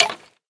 skeletonhurt1.ogg